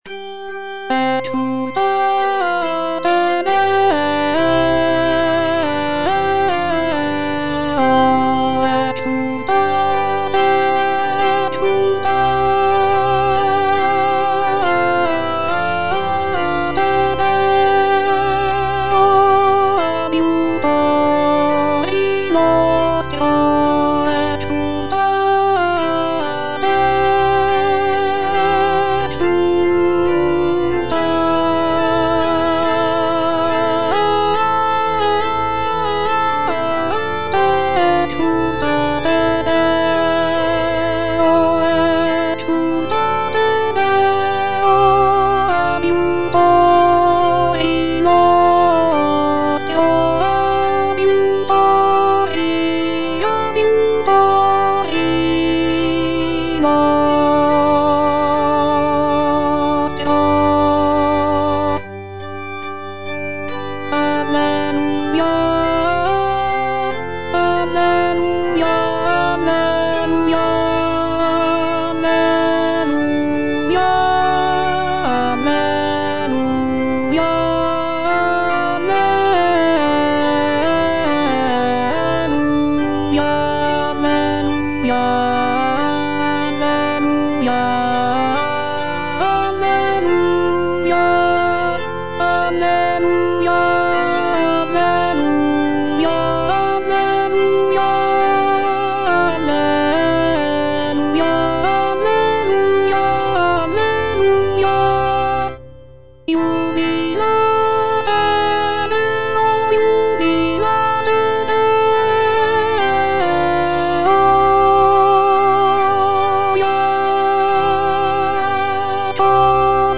Alto (Voice with metronome) Ads stop
a sacred choral work